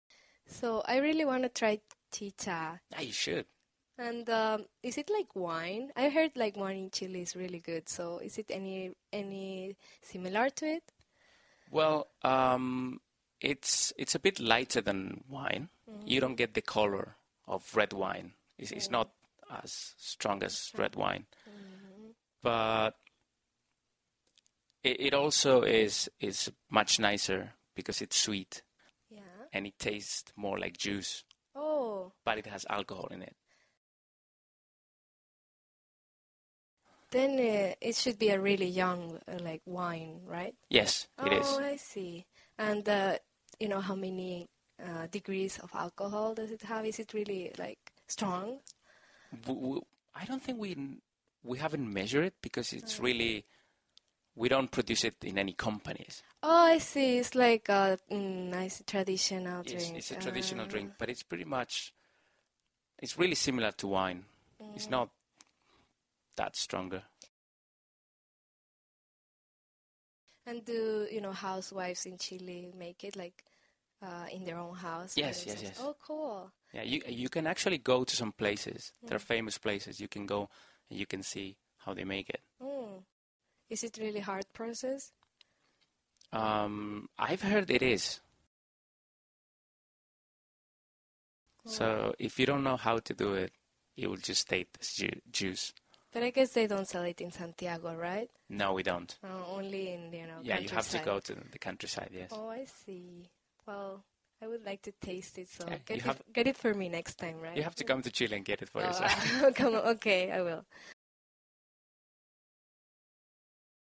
在线英语听力室英文原版对话1000个:1205 Chi Cha的听力文件下载,原版英语对话1000个,英语对话,美音英语对话-在线英语听力室